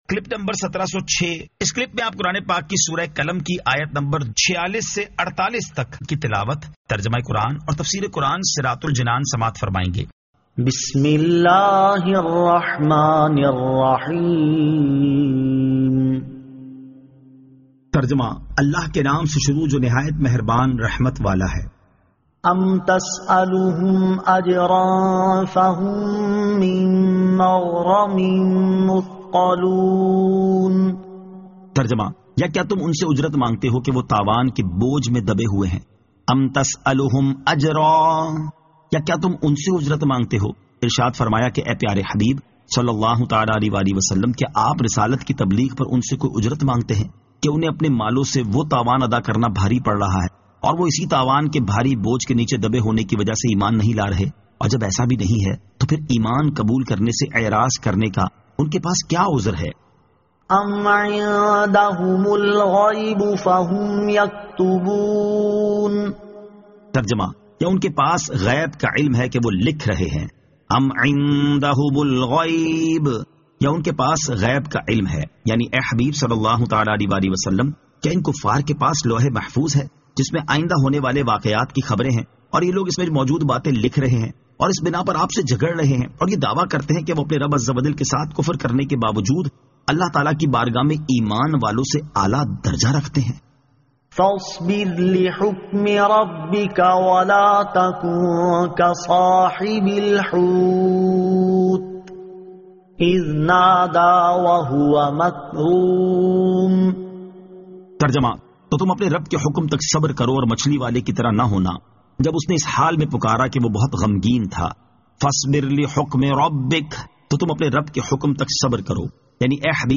Surah Al-Qalam 46 To 48 Tilawat , Tarjama , Tafseer